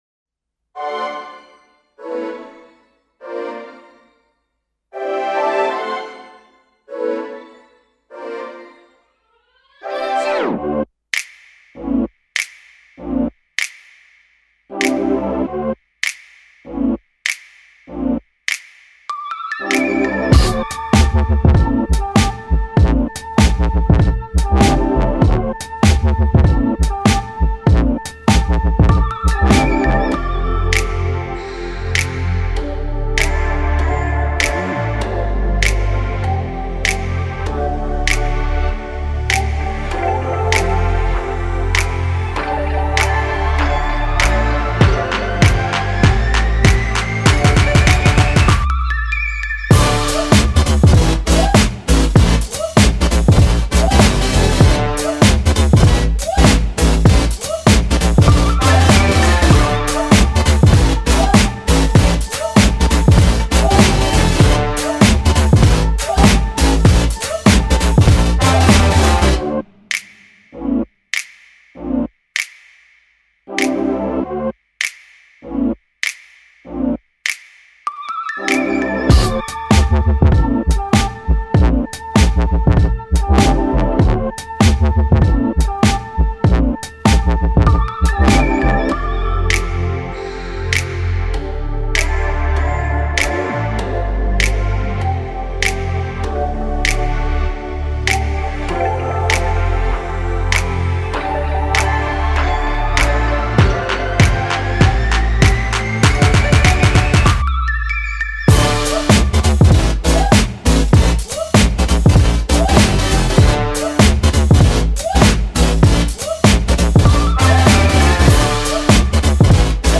K-Pop Instrumental